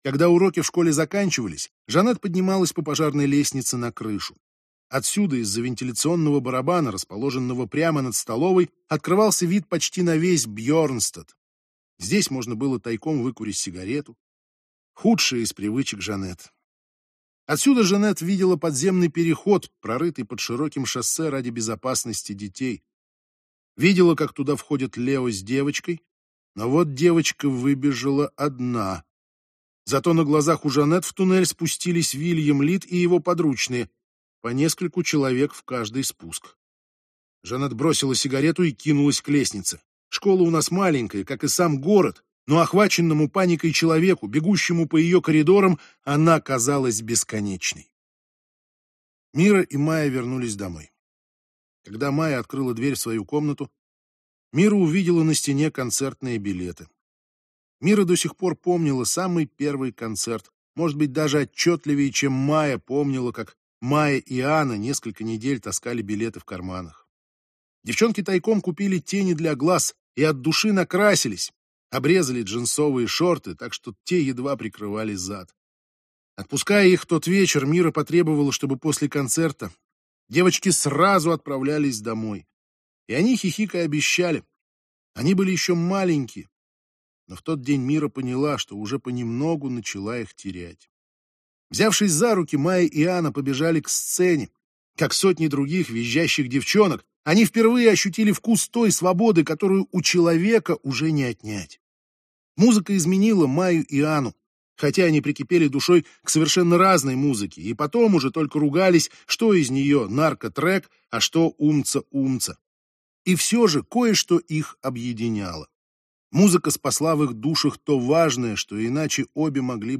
Аудиокнига Мы против вас - купить, скачать и слушать онлайн | КнигоПоиск